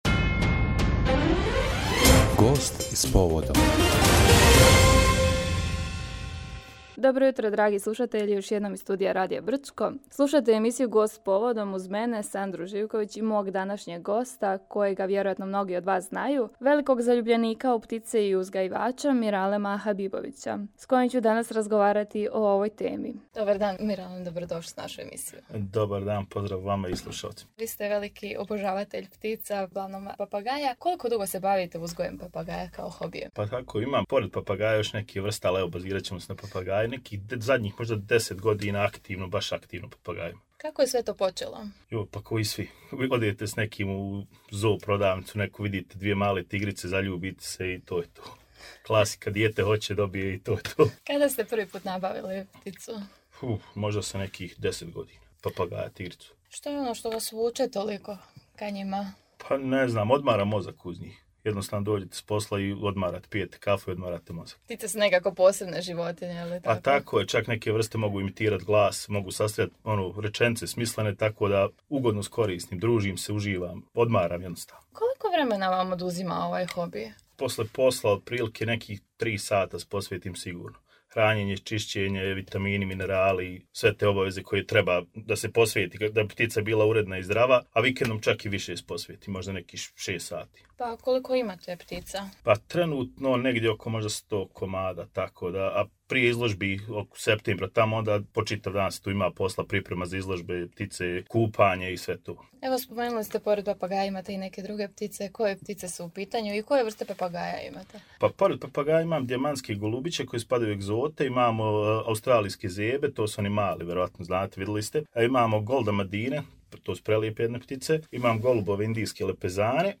uzgajivač i ljubitelj ptica